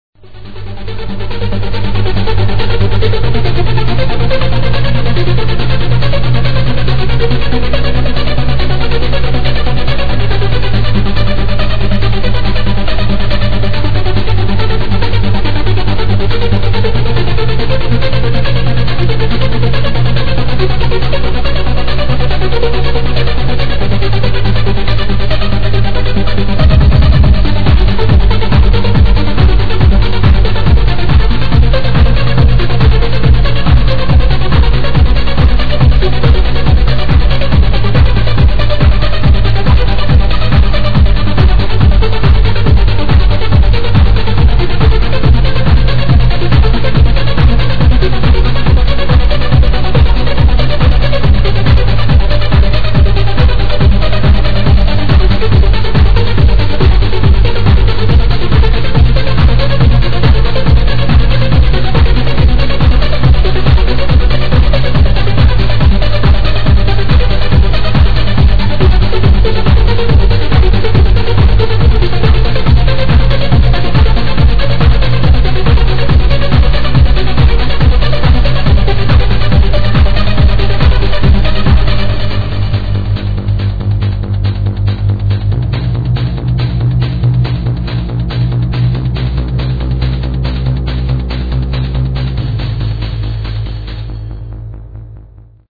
sounds a little too amateaurish to me
Damm thats a good track, real dark mother